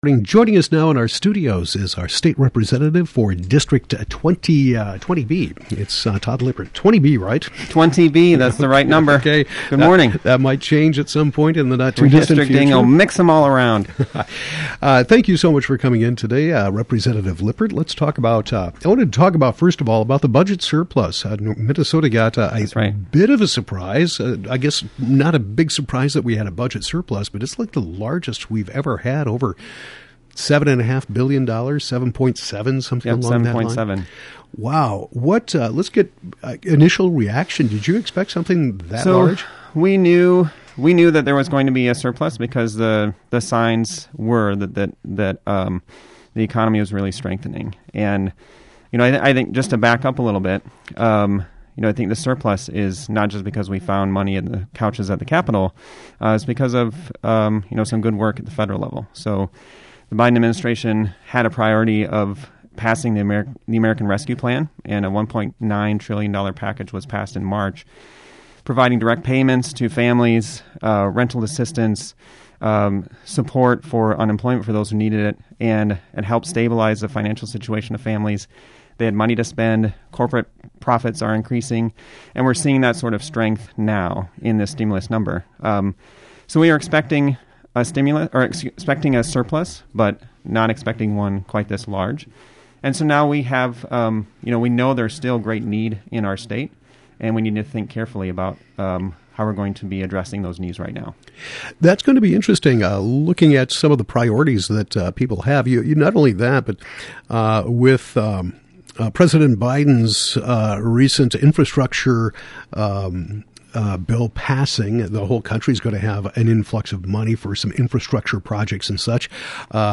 State Representative Todd Lippert discusses the Minnesota budget surplus and more.